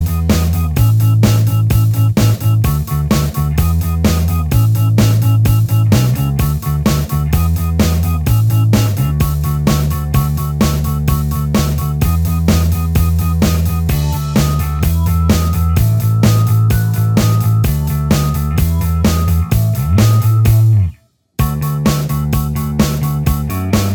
Minus Guitars Rock 3:32 Buy £1.50